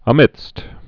(ə-mĭdst)